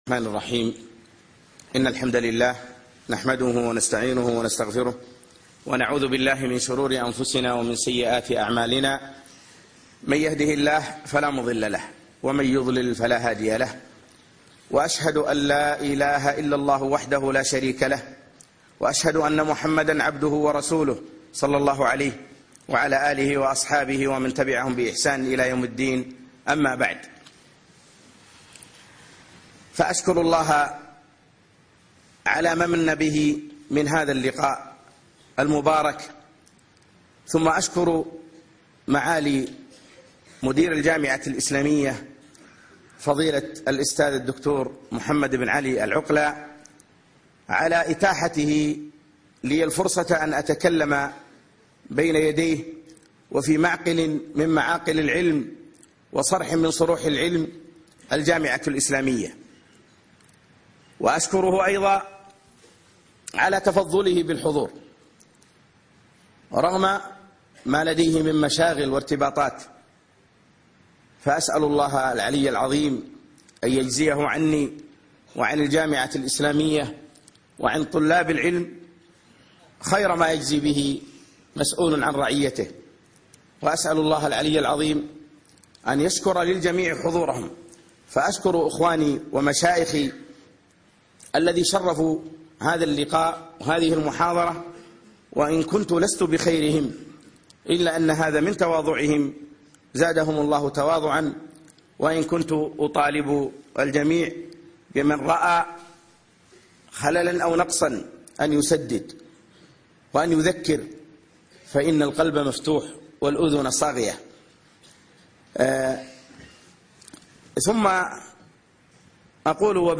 الإستشفاء بالقرآن محاضرة في الجامعة الإسلامية بالمدينة النبوية